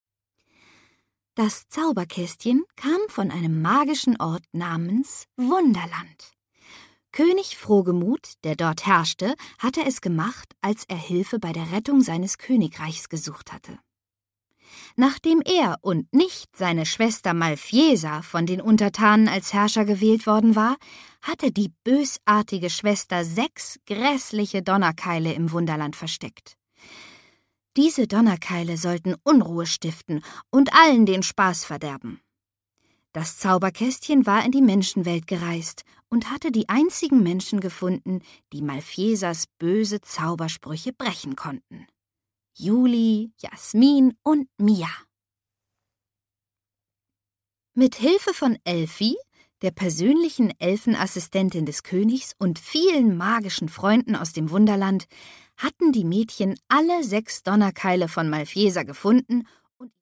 Fassung: Ungekürzte Lesung
MP3 Hörbuch-Download